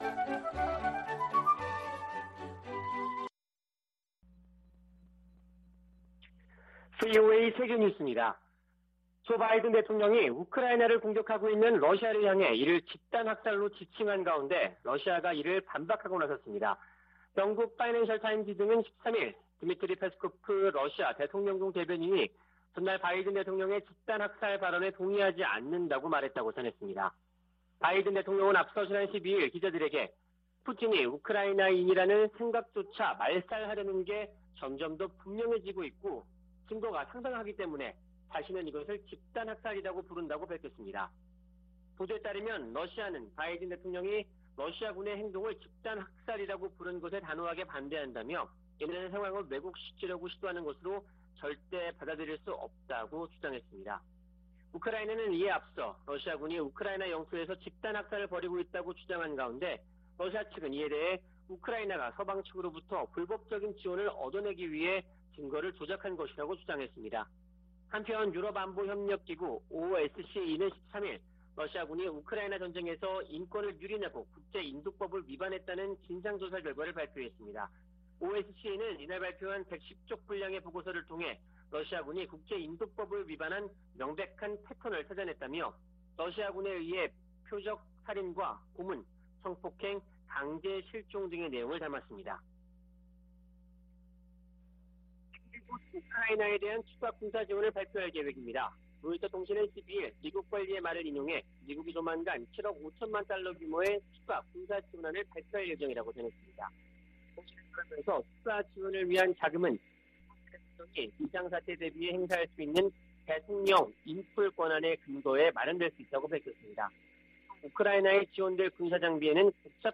VOA 한국어 아침 뉴스 프로그램 '워싱턴 뉴스 광장' 2022년 4월 14일 방송입니다. 미 국무부는 연례 인권보고서에서 북한이 세계에서 가장 억압적이고 권위주의적인 국가라고 비판했습니다. 미 국방부는 북한이 전파 방해와 같은 반우주역량을 과시하고 있으며, 탄도미사일로 인공위성을 겨냥할 수도 있다고 평가했습니다. 한국 윤석열 차기 정부의 초대 외교부와 통일부 장관에 실세 정치인들이 기용됐습니다.